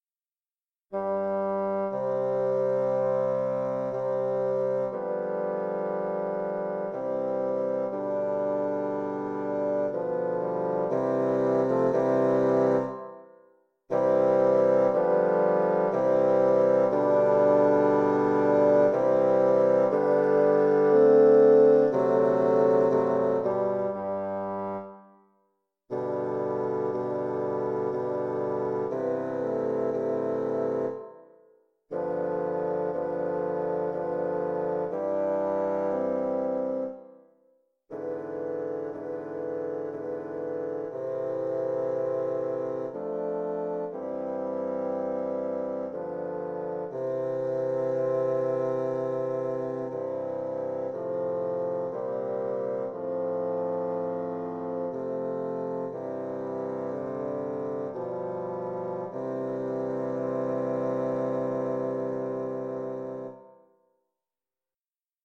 Intermediate Bassoon Quartet
Instrumentation: Bassoon Quartet
tags: bassoon music